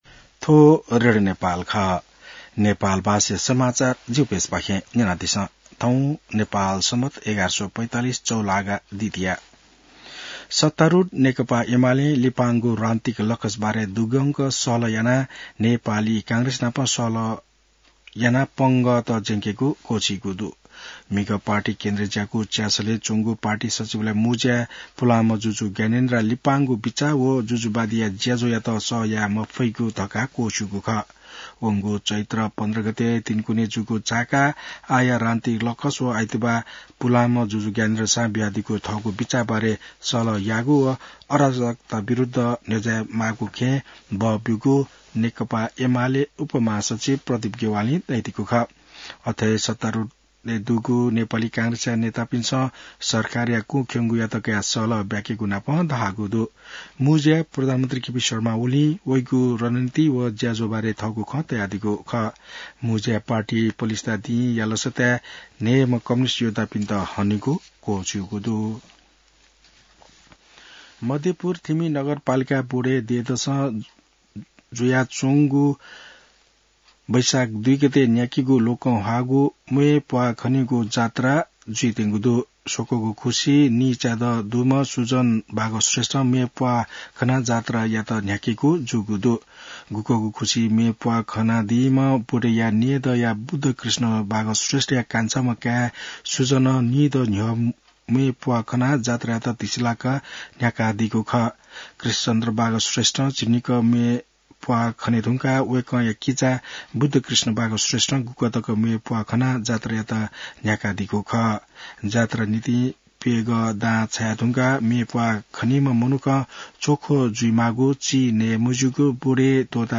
नेपाल भाषामा समाचार : २ वैशाख , २०८२